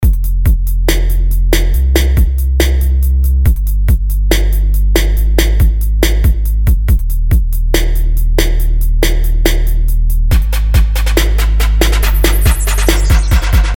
小军鼓样本
描述：小军鼓样本
标签： 小军鼓 配音 舞蹈
声道立体声